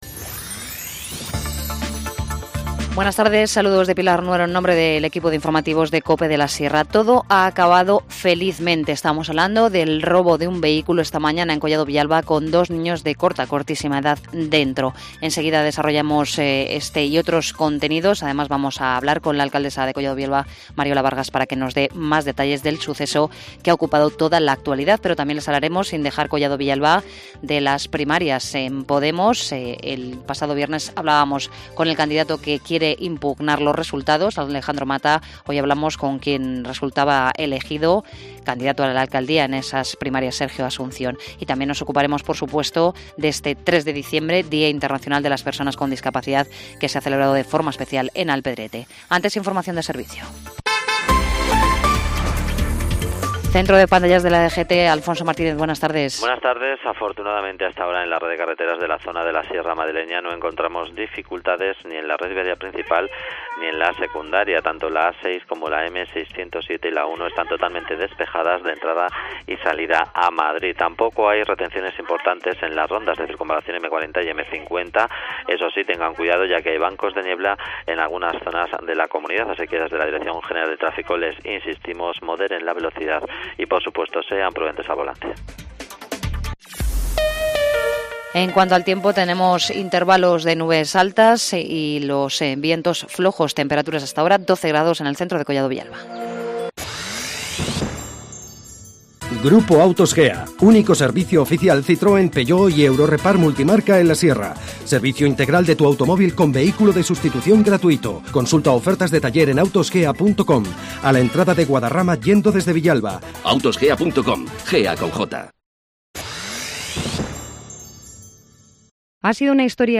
INFORMACIÓN LOCAL